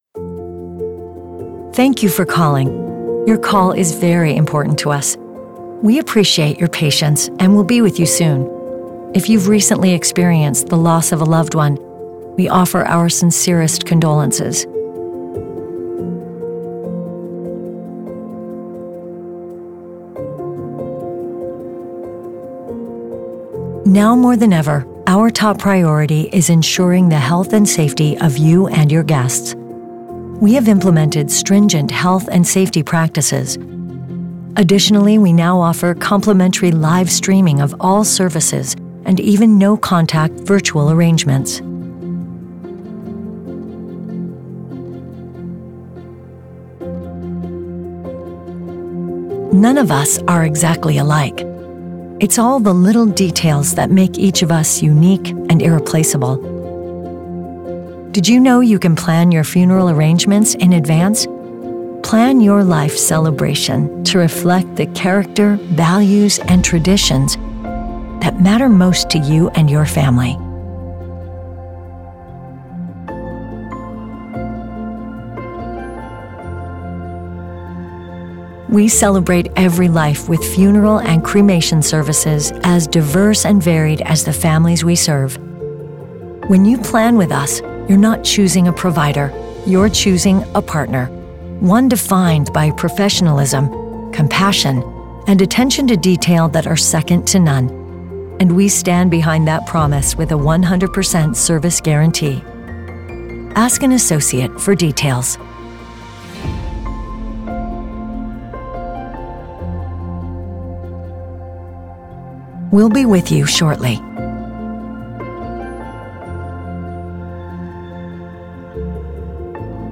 Dignity Memorial On-Hold Message
on-hold-message-funeral-covid-reference-wav